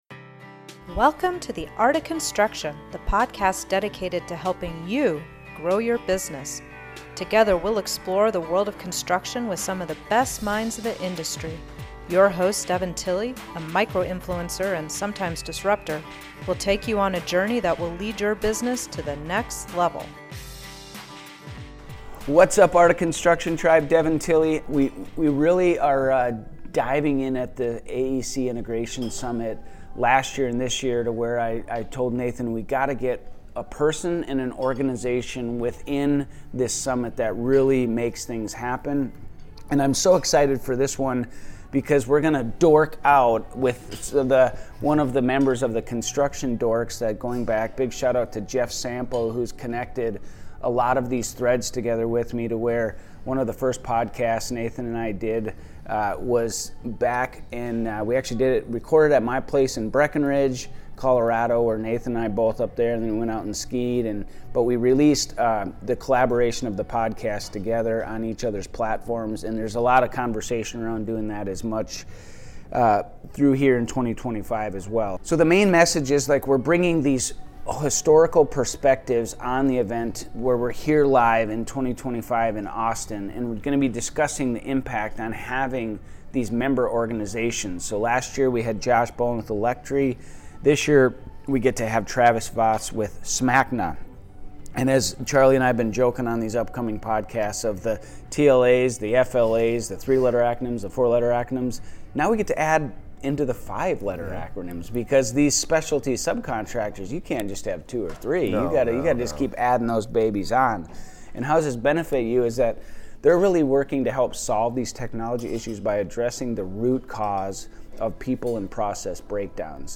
If you hear any background noise, that's because this episode was recorded LIVE at the AEC Integration Summit in Austin!